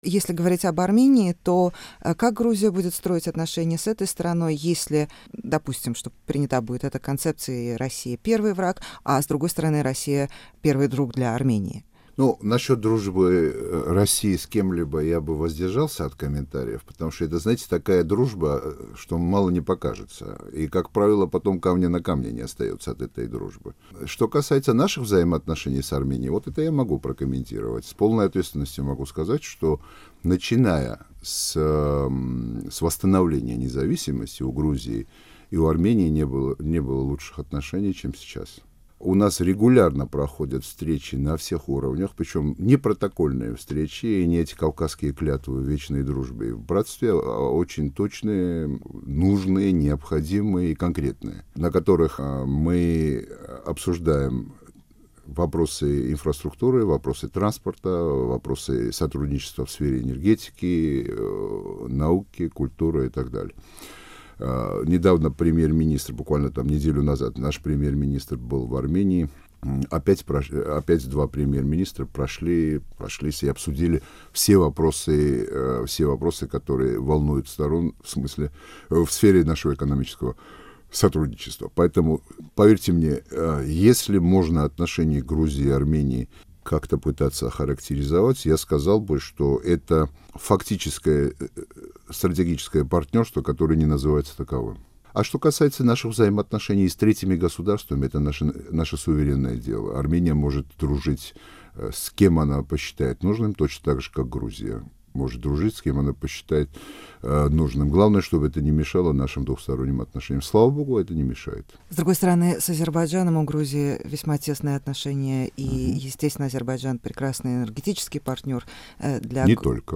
Из интервью главы МИД Грузии Григола Вашадзе